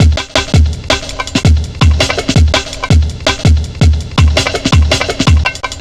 Index of /90_sSampleCDs/Zero-G - Total Drum Bass/Drumloops - 3/track 44 (165bpm)